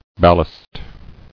[bal·last]